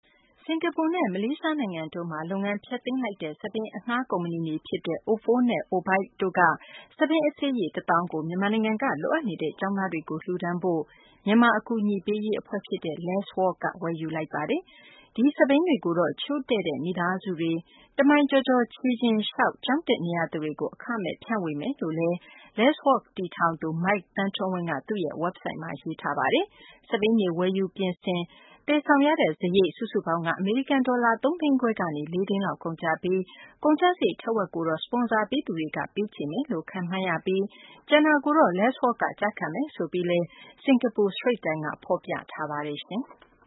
သမ္မတ Trump နဲ့ ဝန်ကြီးချုပ် May ပူးတွဲသတင်းစာရှင်းလင်းပွဲ